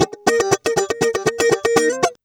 120FUNKY02.wav